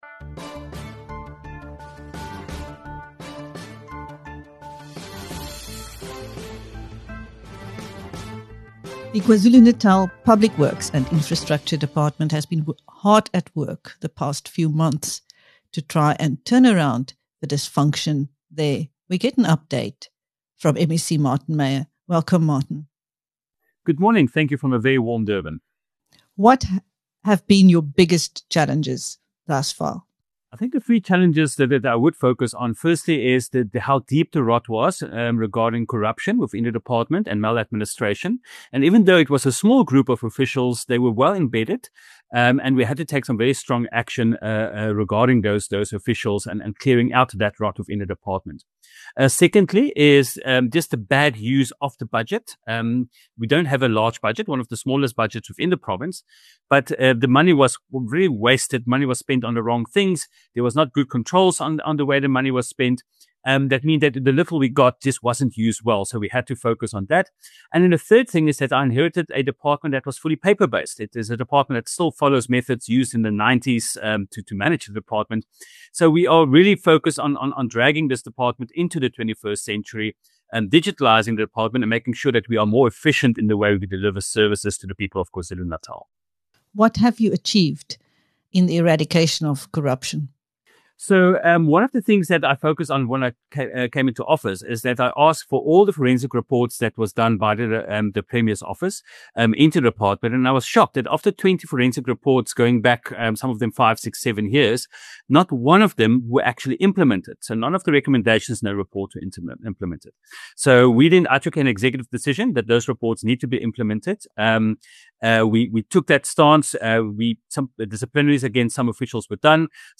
In this interview with BizNews, MEC Martin Meyer gives an update of the milestones reached.